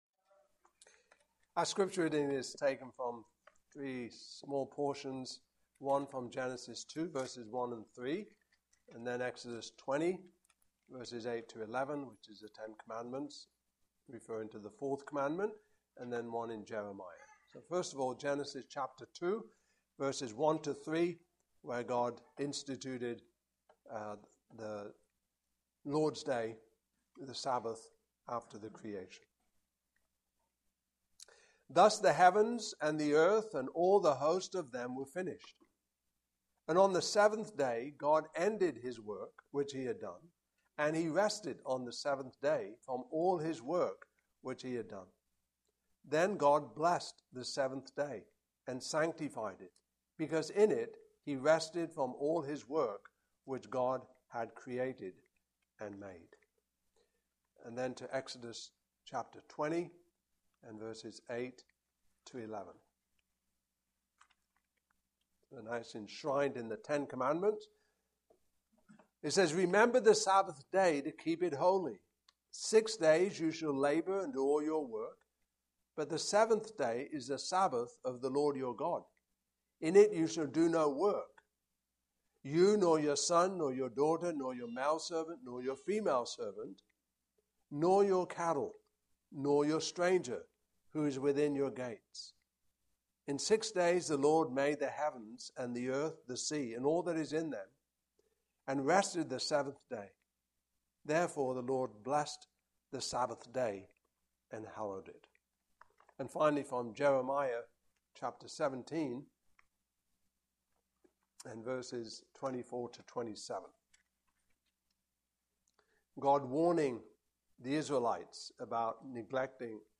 Passage: Genesis 2:1-3, Exodus 20:8-11, Jeremiah 17:24-27 Service Type: Evening Service